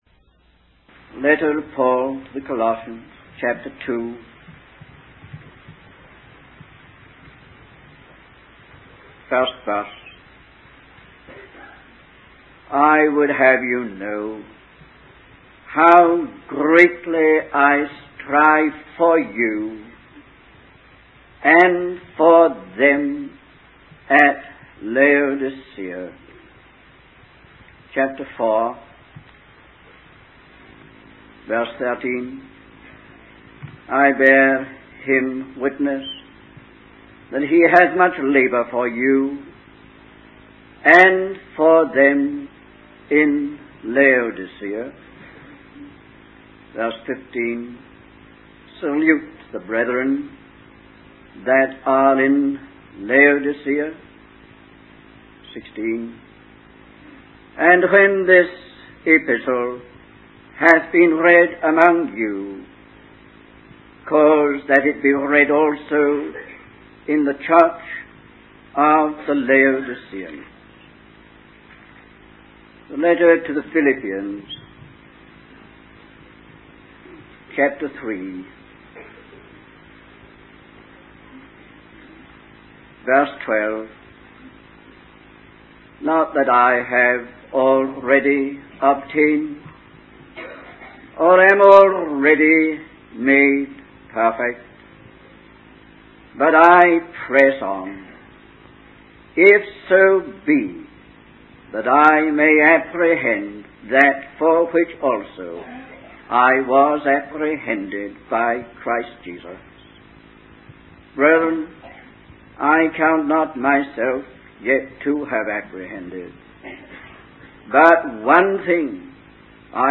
In this sermon, the preacher emphasizes the importance of having a single-minded focus on God and His calling. He urges the listeners not to settle for less than what God intended for them when He saved them.